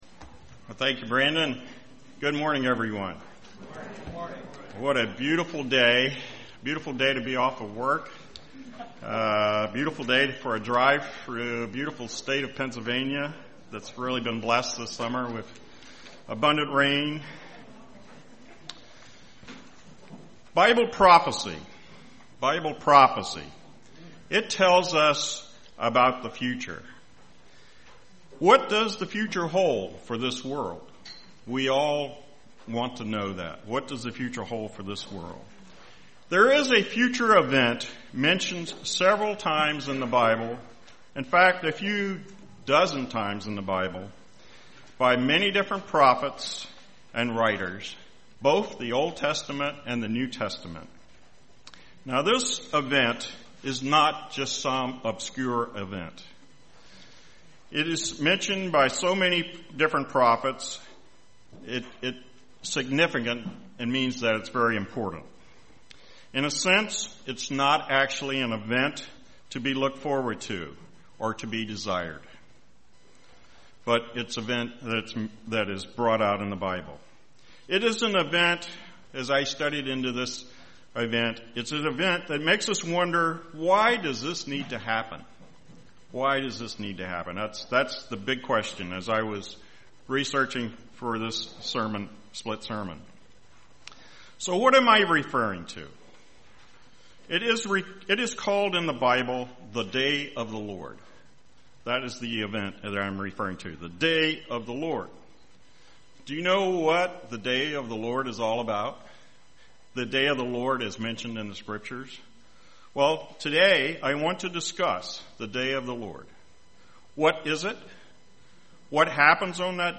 This sermon explains what the Day of the Lord is and why there is going to be a Day of the Lord in the future. It looks at many Old Testament and New Testament scriptures that discuss this event.